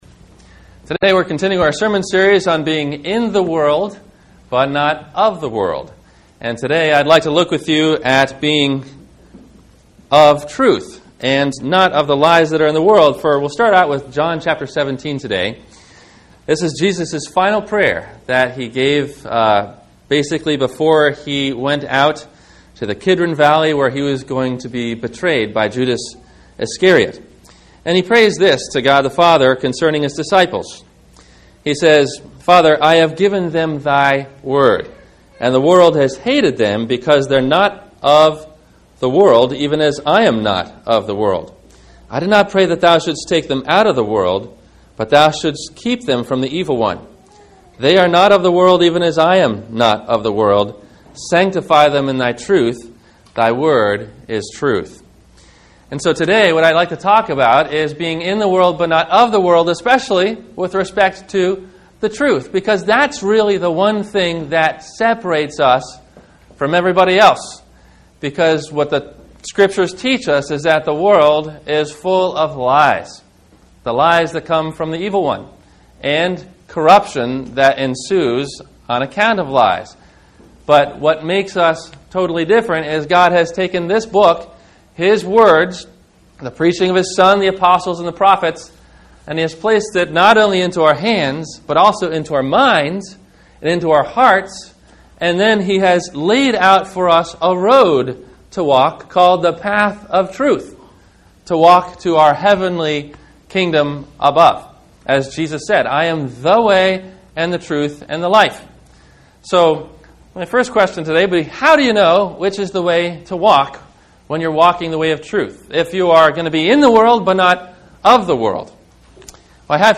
We Buy Truth - Sermon - September 20 2009 - Christ Lutheran Cape Canaveral